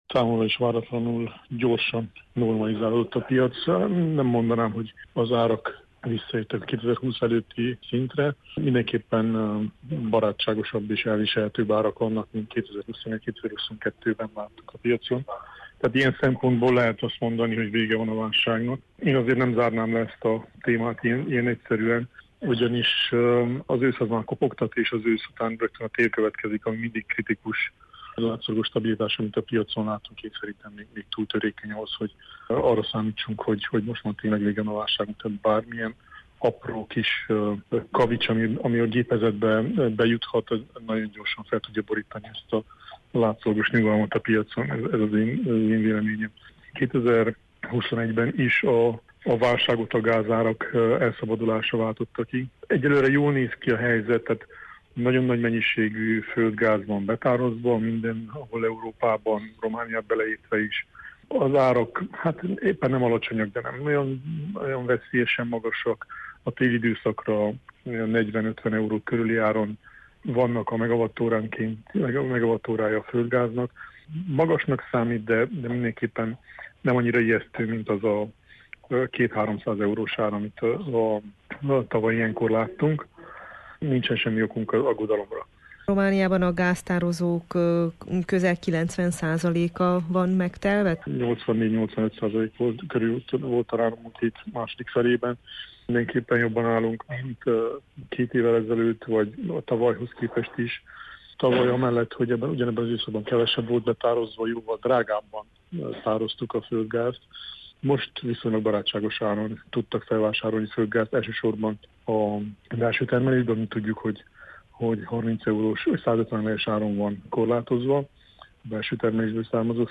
energiaügyi szakembert kérdezte